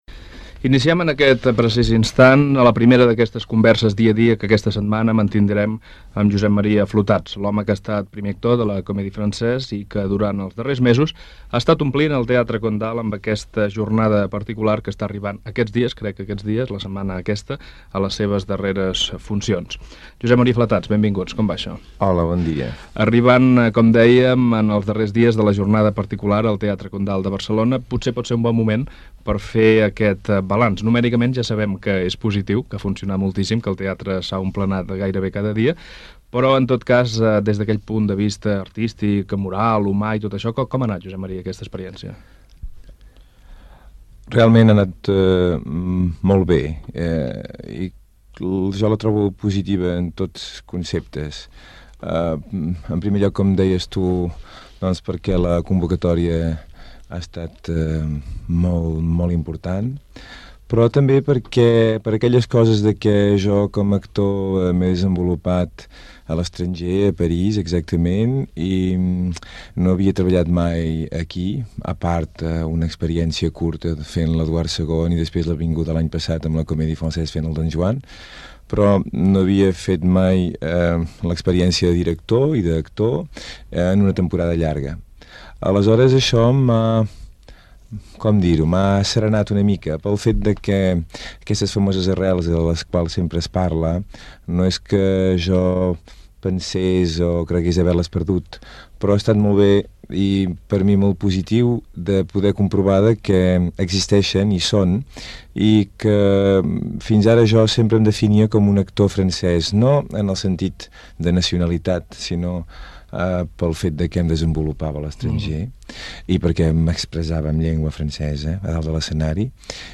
Entrevista a l'actor Josep Maria Flotats sobre la seva trajectòria i com ha estat el seu retorn a Catalunya.
Info-entreteniment